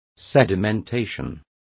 Complete with pronunciation of the translation of sedimentation.